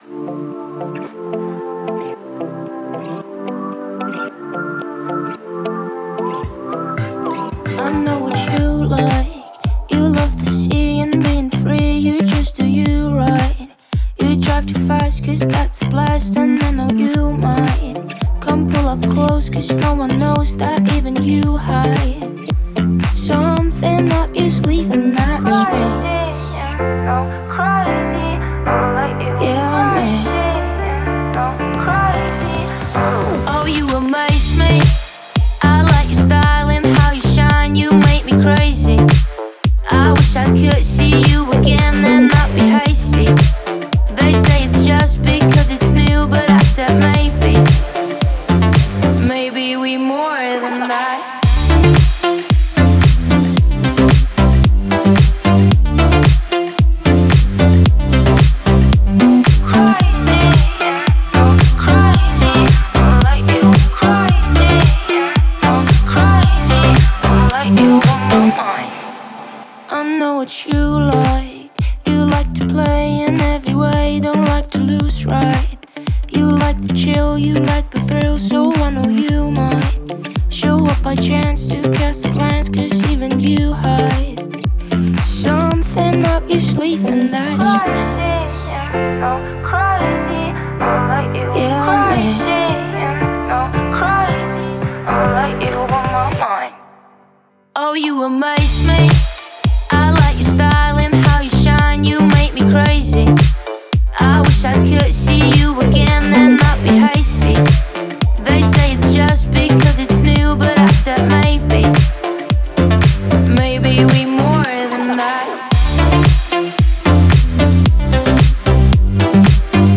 это нежная и мелодичная песня в жанре инди-поп